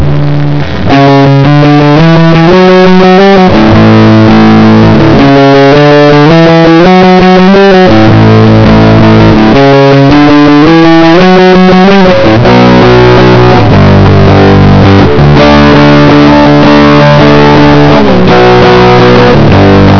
Distorted music sample